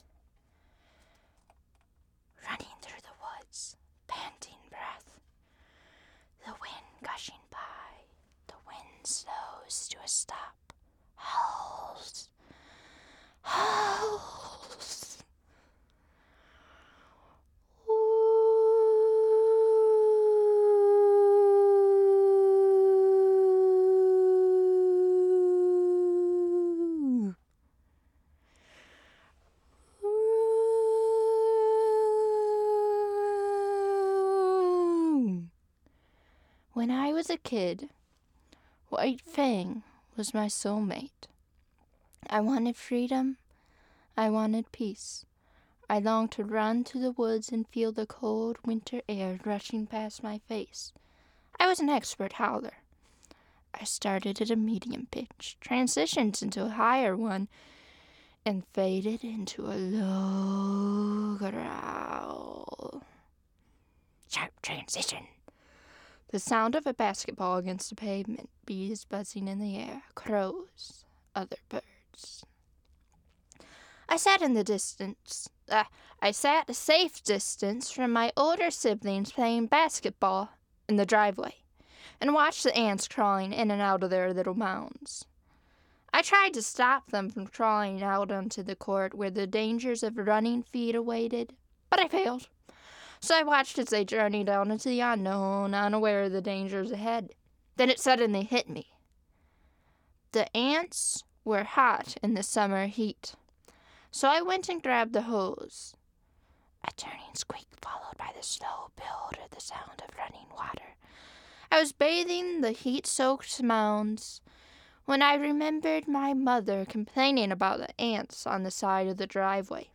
I have a surprisingly deep voice.